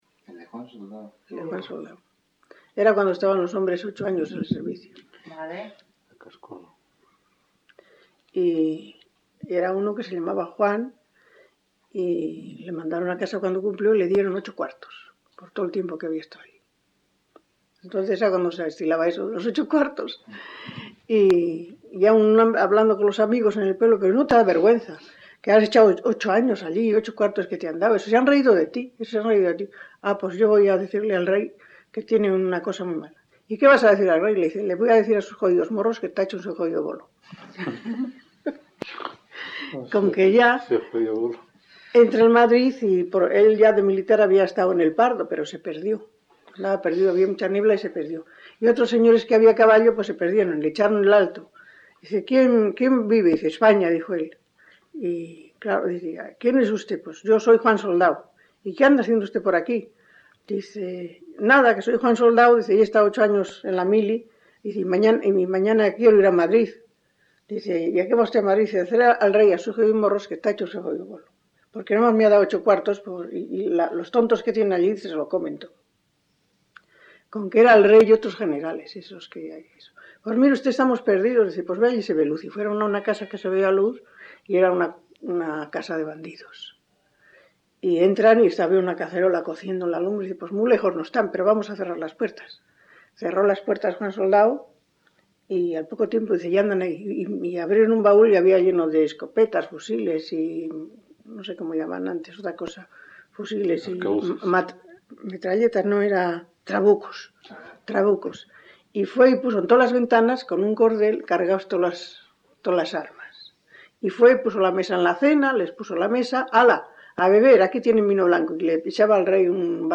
Grabación realizada en La Overuela (Valladolid), en 1977.